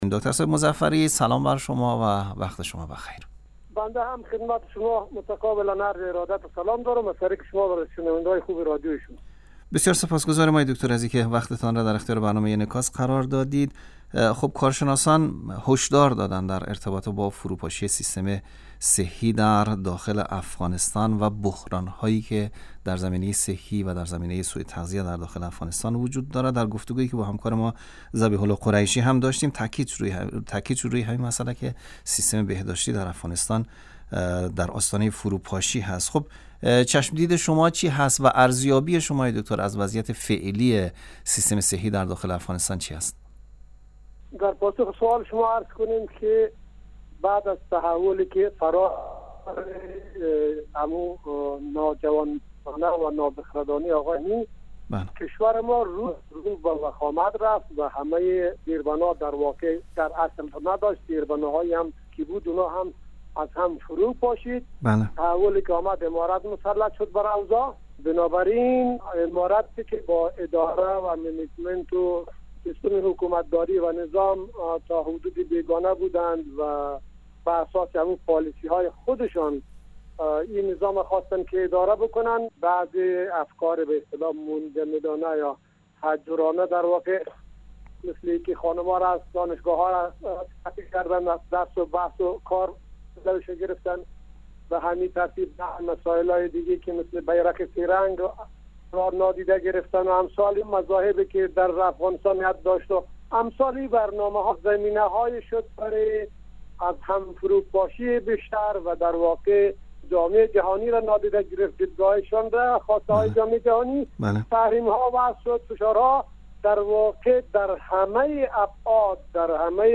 کارشناس افغان و آگاه امور درمانی در گفت و گو با خبرنگار رادیو دری